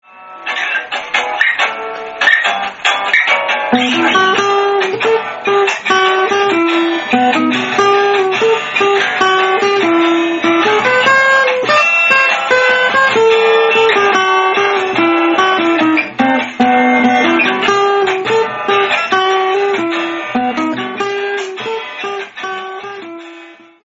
アコースティックギターインスト ゼロゼロヘブン 試聴あり 新曲を練習開始」
今までにない曲風になったと思います。
本日より合わせ始めました。
ジプシー風+クリフ・リチャード「Early In The Morning」+ちょびっとオルタナティブに仕上げたいです。
リードギター